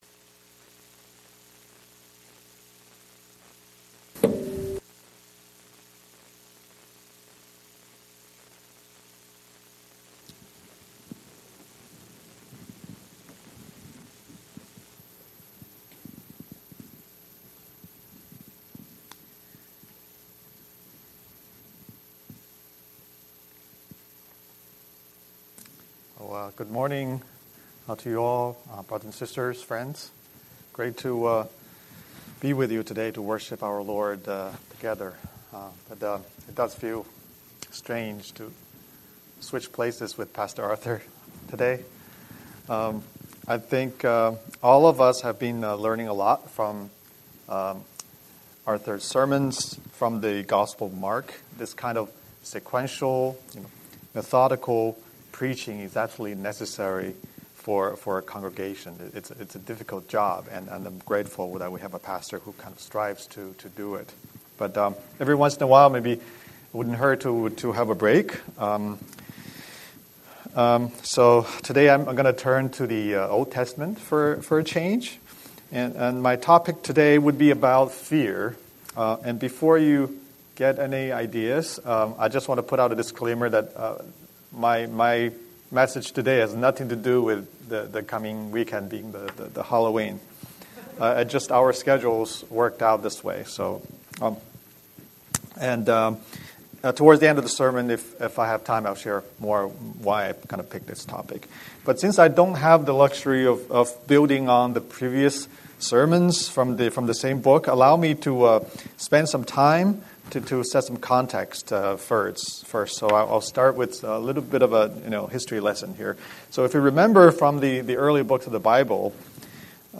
Scripture: 1 Samuel 12:12–25 Series: Sunday Sermon